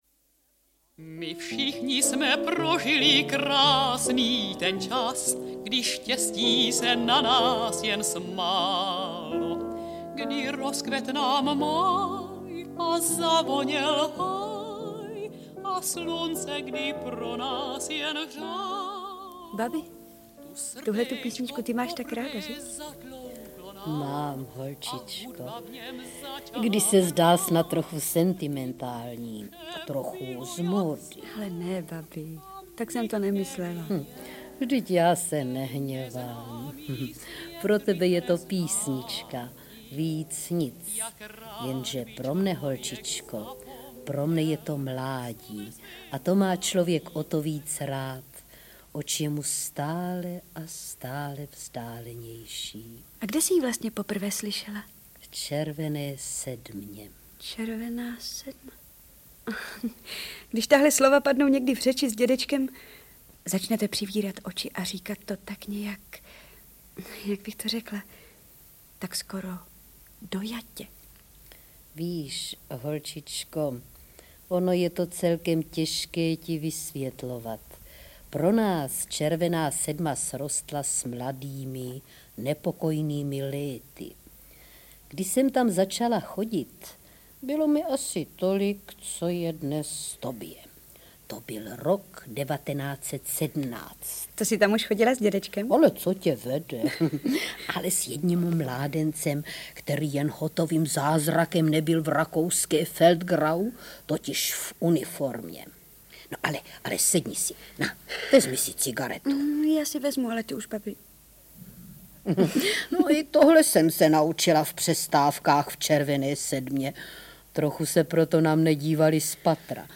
montáž z písní